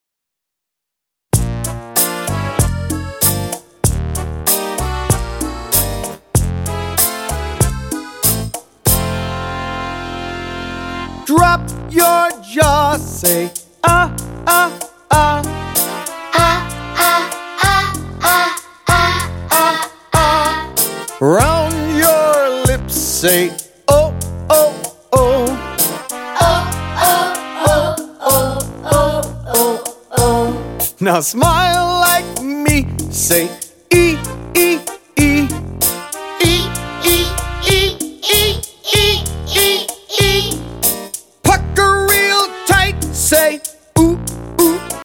-23 simple, catchy songs
-Kids and adults singing together and taking verbal turns